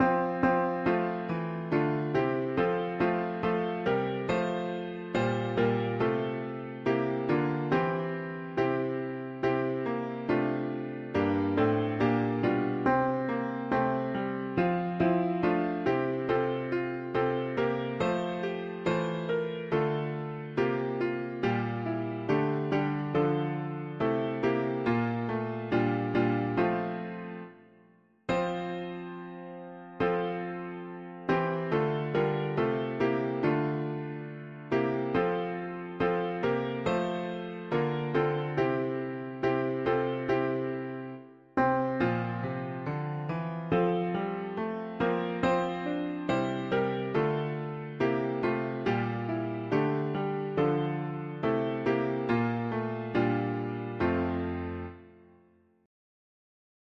Key: F major
Alternate words Lord of the dance Tags english secular 4part chords